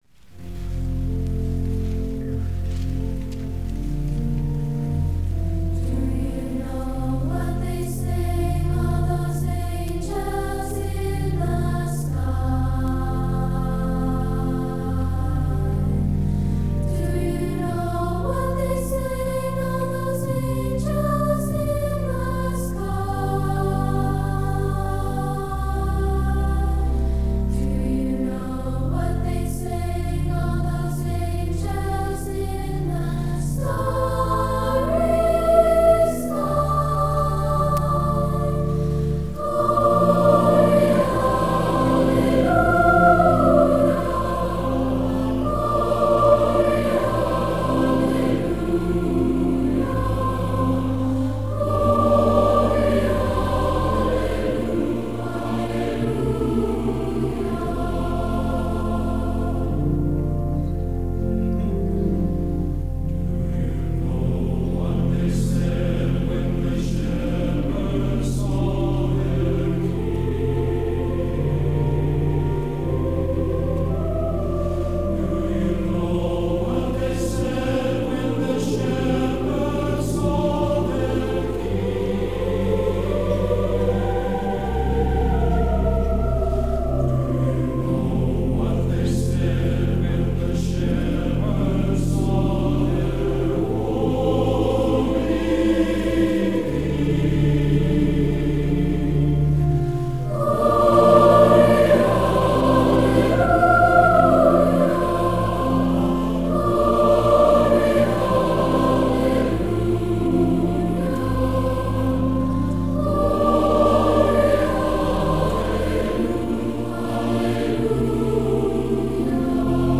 SATB or SSA, Christmas